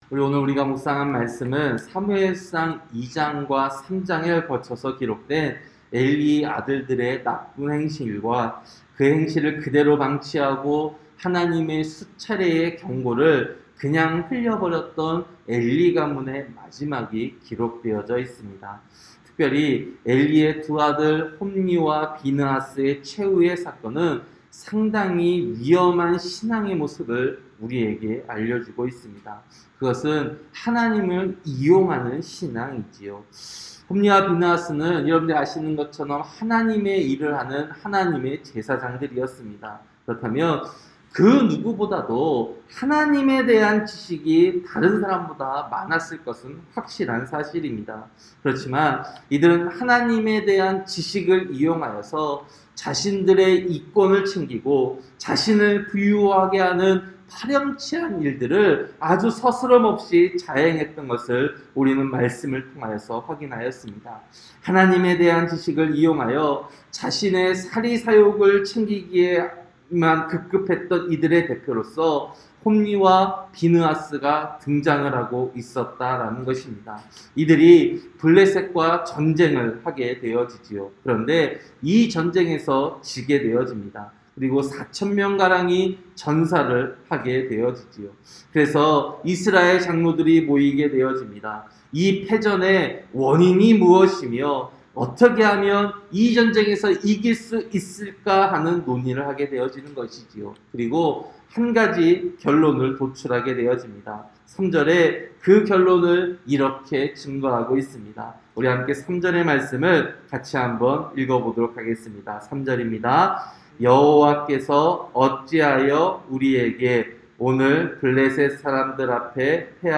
새벽기도-사무엘상 4장